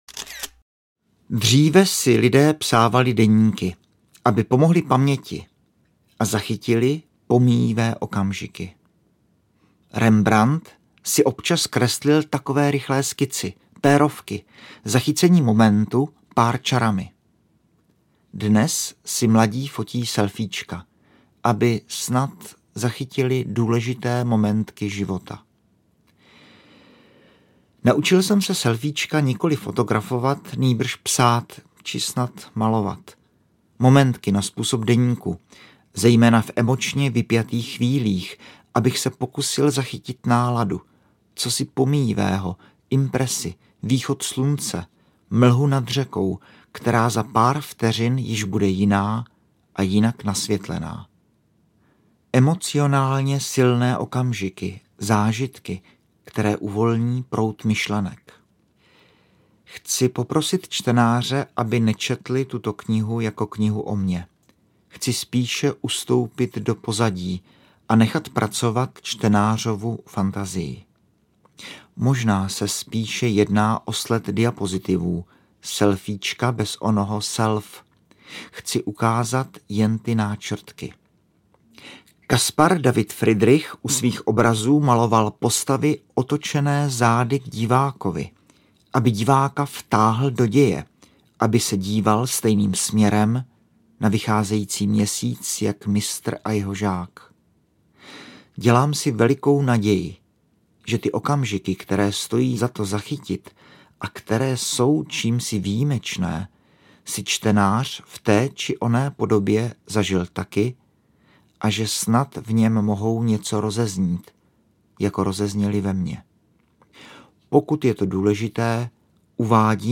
Selfíčka audiokniha
Ukázka z knihy
Autorské podání textu nabízí další rozměr a umožňuje prožít ještě silnější zážitek z knihy.
• InterpretMarek Orko Vácha